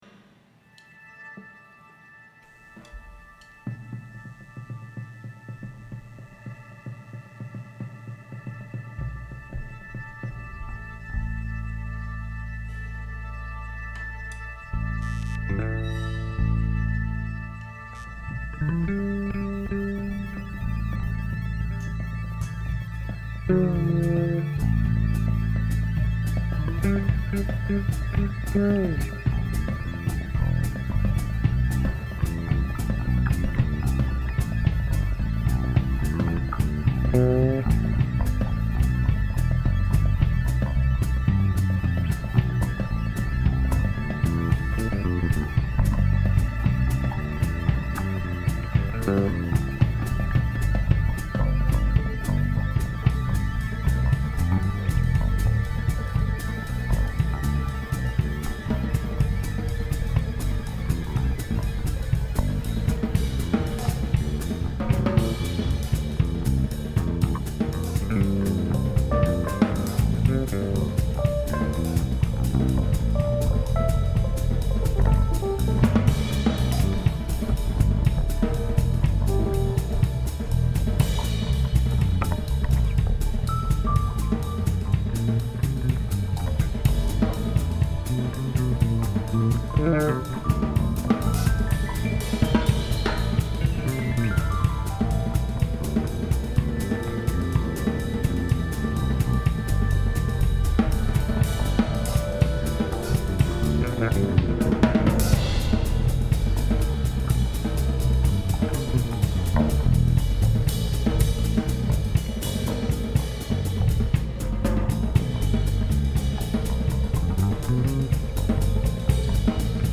keyboards, percussion
drums, percussion
bass